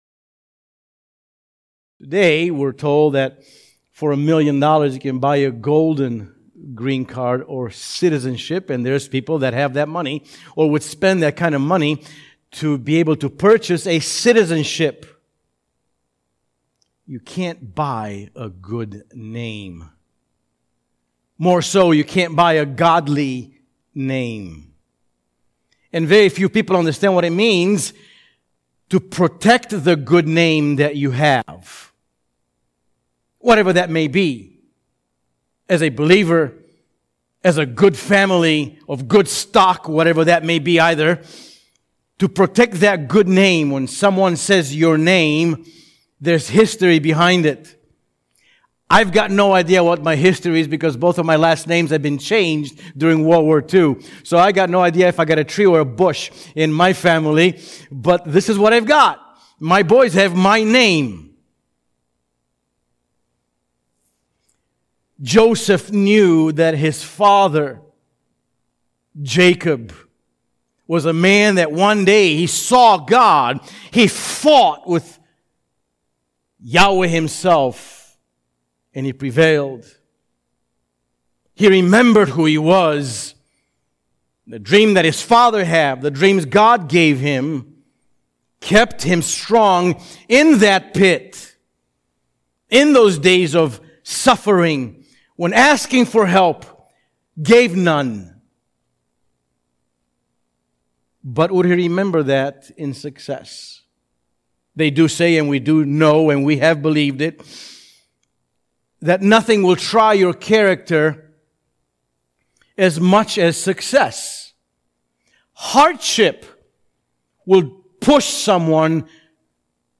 All Sermons